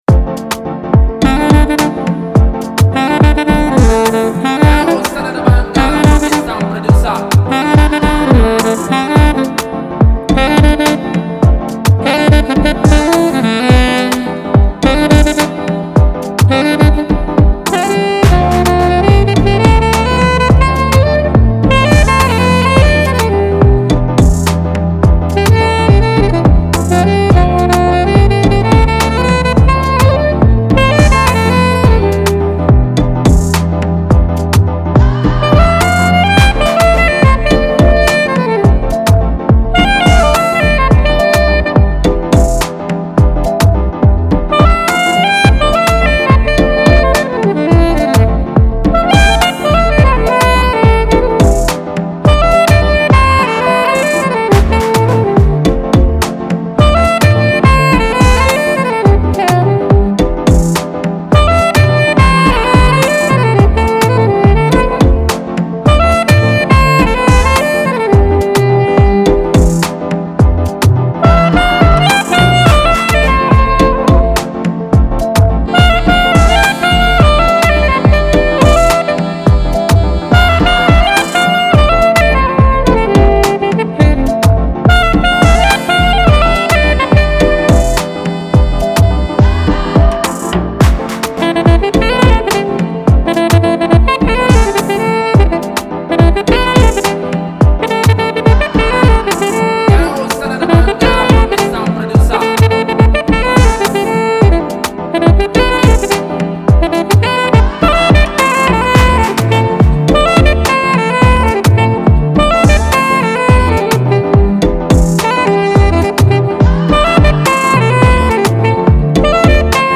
Top notch Nigerian sax Instrumentalist and talented artist
sax tune